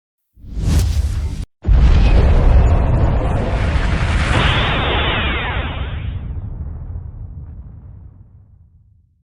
Nuclear Implosion
SFX
yt_Ua-PapmIPw4_nuclear_implosion.mp3